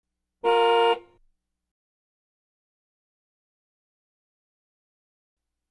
Звуки клаксона
Автомобильный звук гудка